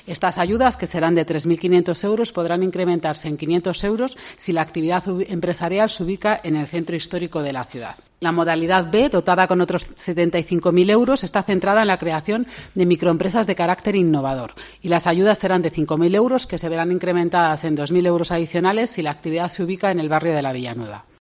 Esmeralda Campos, concejala de Promoción Económica, explicaba los detalles de ambas.